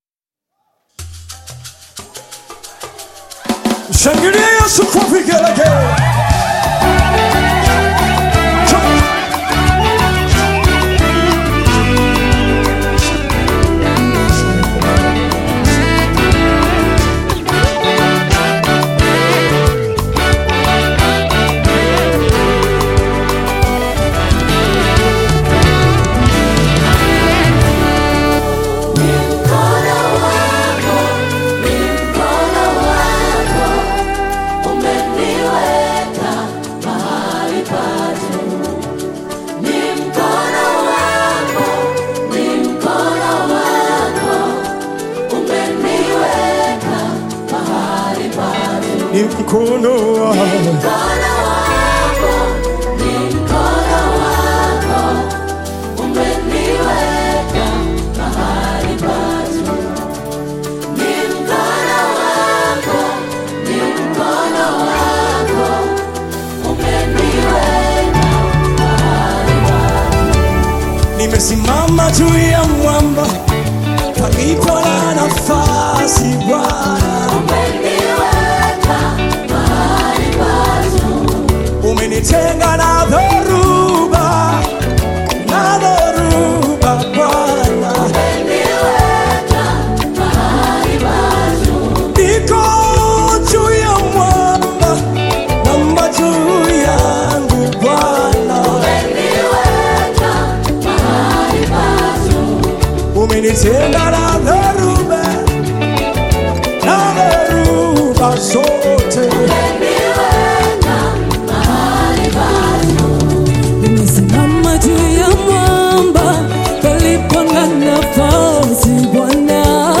Nyimbo za Dini Praise music
Praise Gospel music track